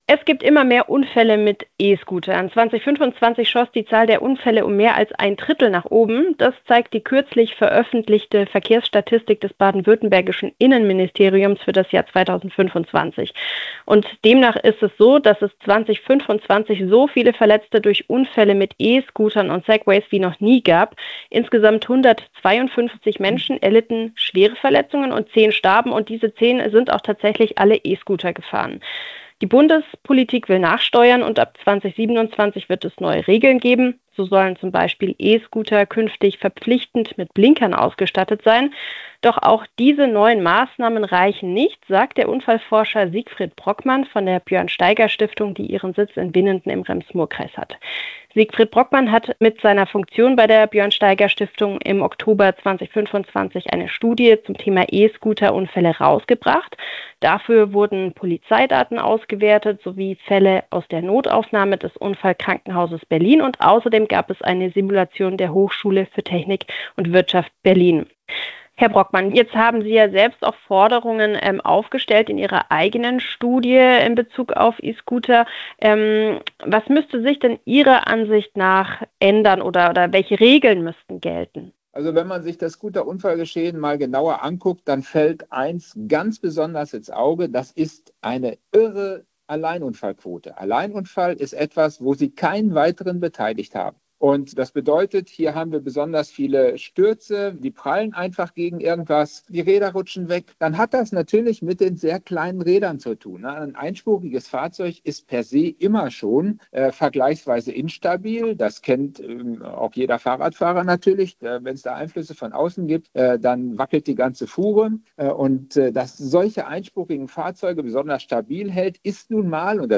Interview: Immer mehr schwere E-Scooter-Unfälle - Was muss sich ändern?